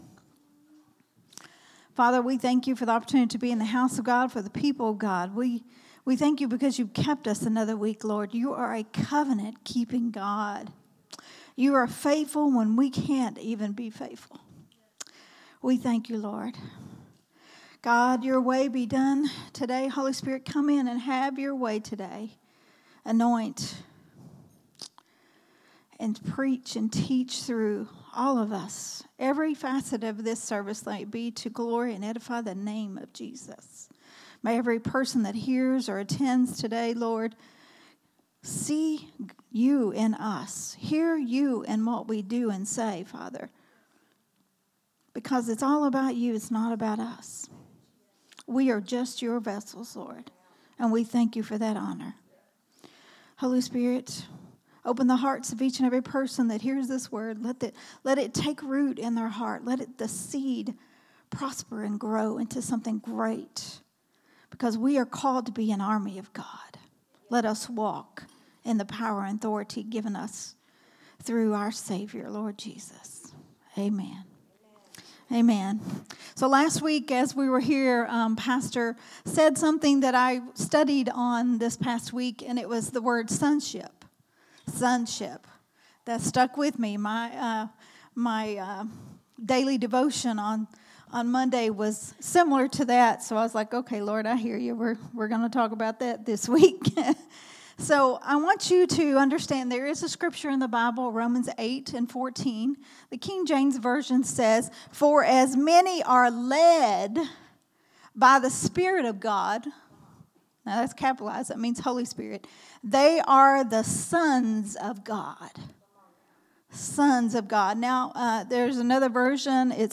teaching
recorded at Growth Temple Ministries